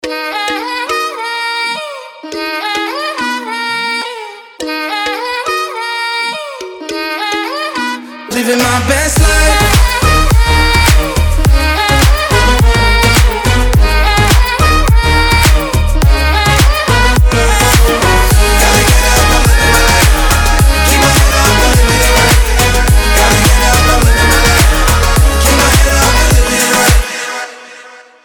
позитивные
громкие
веселые
Moombahton
Позитивный мумбатончик